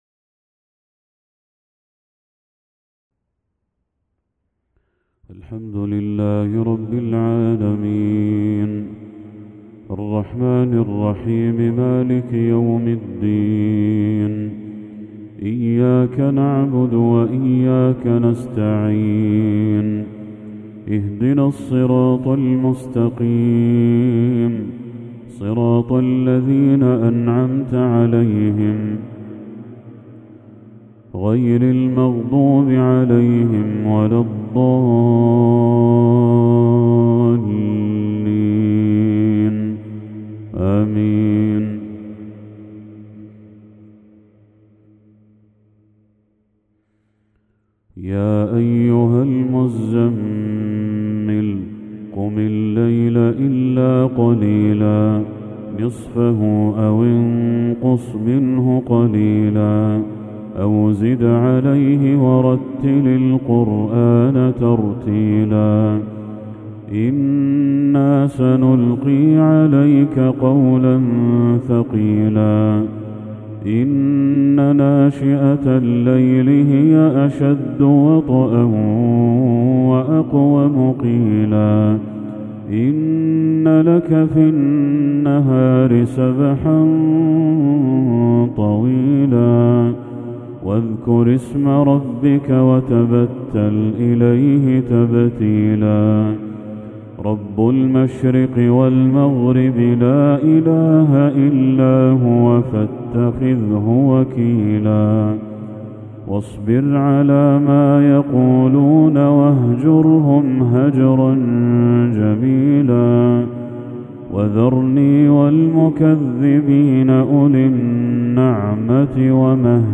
تلاوة خاشعة لسورة المزمل كاملة بلمحات من الشيخ علي جابر -رحمه الله- | عشاء 1 ذو الحجة 1445هـ > 1445هـ > تلاوات الشيخ بدر التركي > المزيد - تلاوات الحرمين